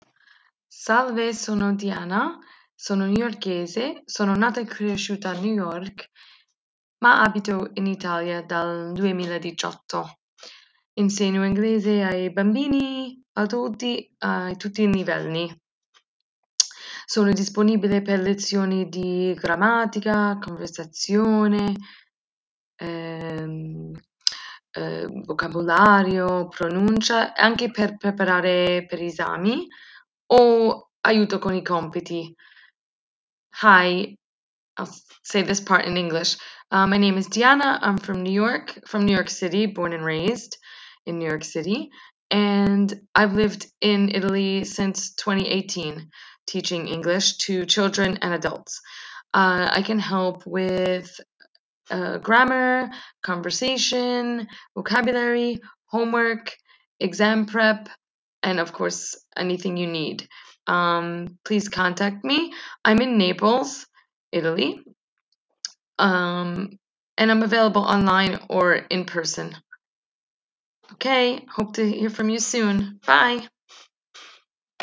Registrare una breve presentazione in Italiano e anche una parte in Inglese, così potenziali studenti potranno sentire la tua voce e il tuo stile di insegnamento.
Native speaker of English from New York City!